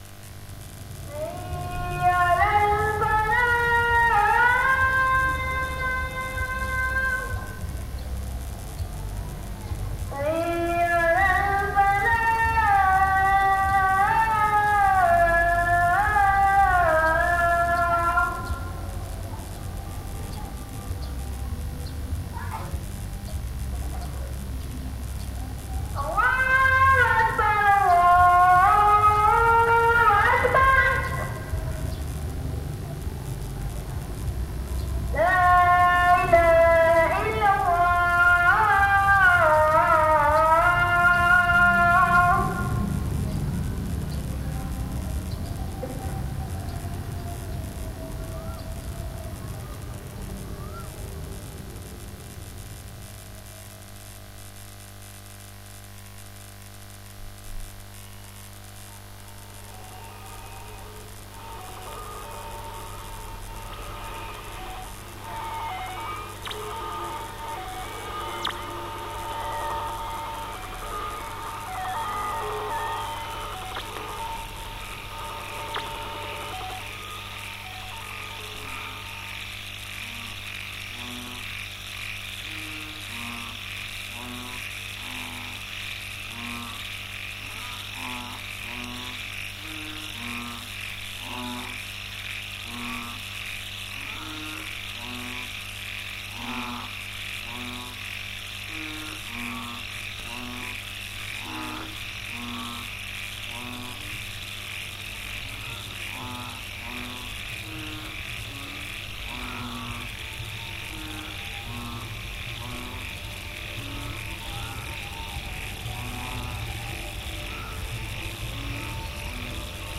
詩的かつサイケデリックなアンビエンスを醸し出した音を楽しめる、東南アジアのフィールド録音作品！
※レコードの試聴はノイズが入ります。商品自体のノイズではありません。